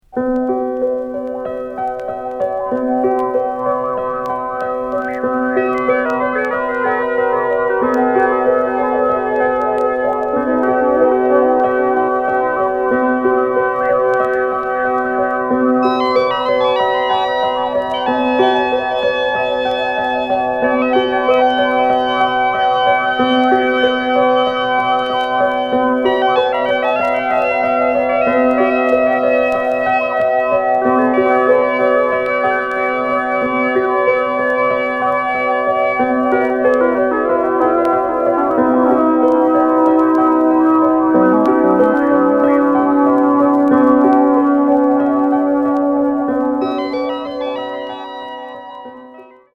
宅録 　電子音